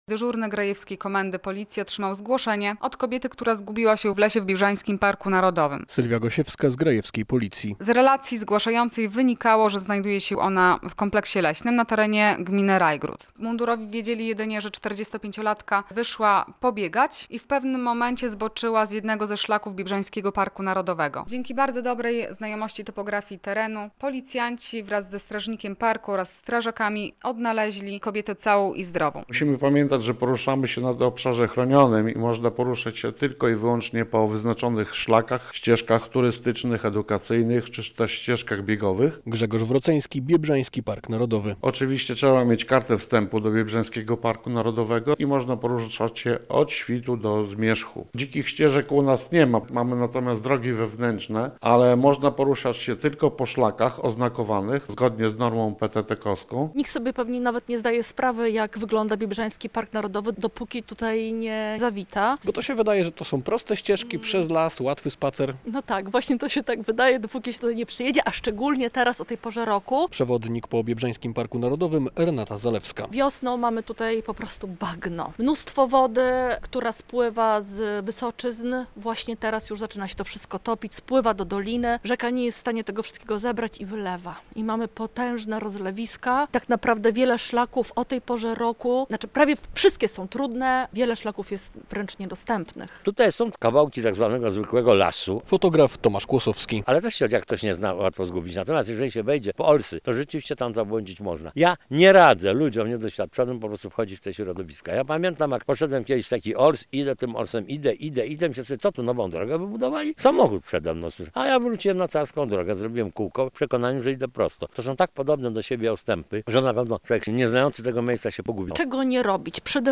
Szlaki w Biebrzańskim Parku Narodowym cieszą się popularnością wśród turystów - bywają jednak bardzo niebezpieczne - relacja